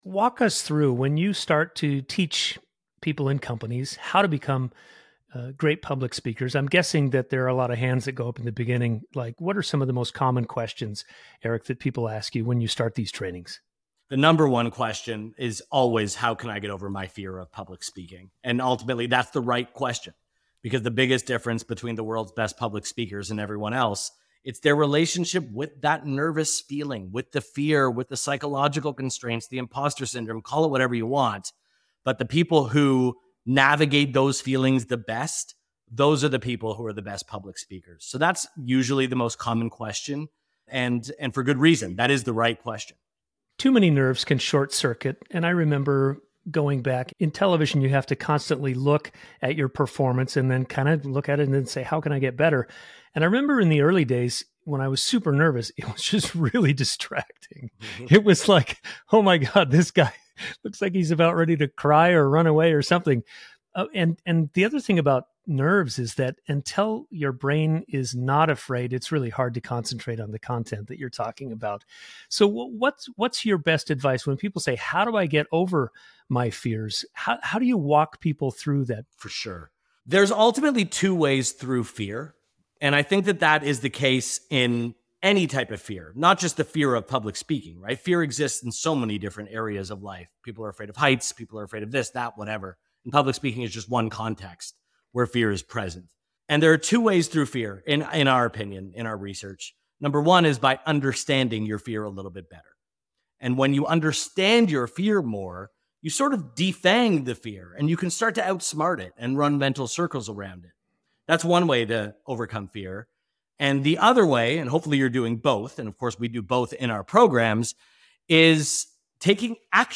Guest: